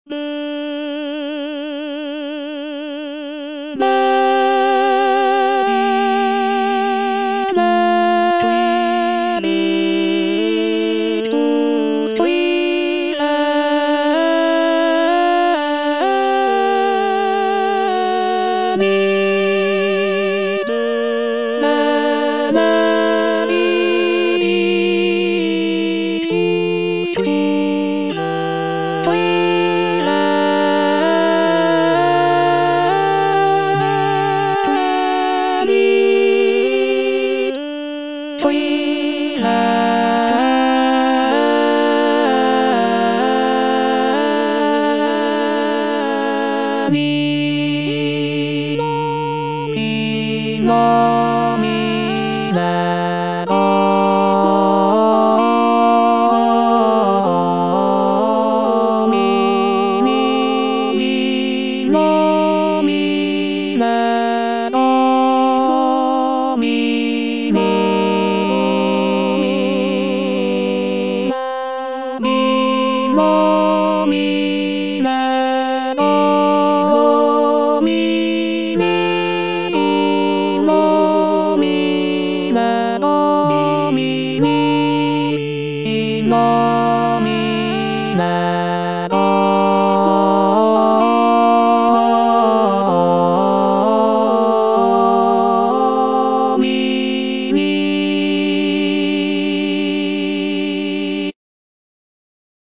T.L. DE VICTORIA - MISSA "O MAGNUM MYSTERIUM" Benedictus - Alto (Emphasised voice and other voices) Ads stop: auto-stop
It is renowned for its rich harmonies, expressive melodies, and intricate counterpoint.